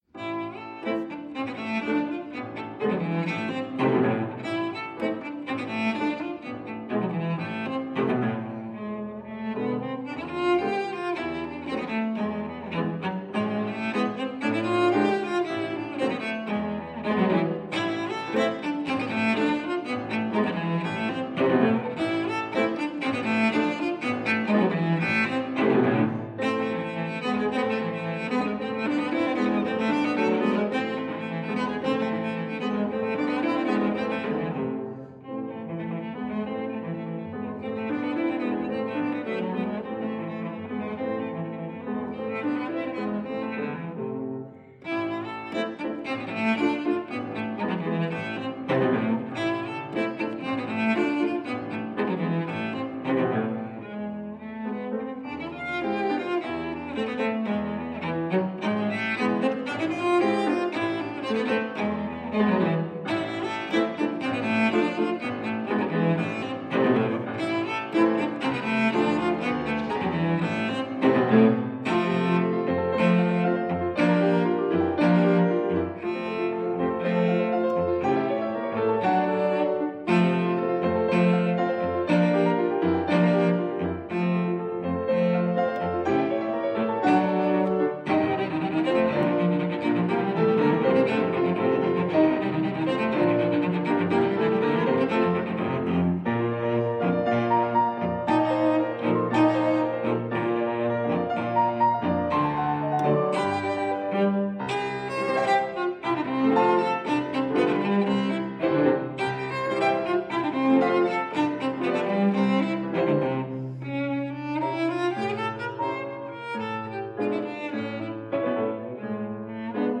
piano
cello | live, Arnold Schoenberg zaal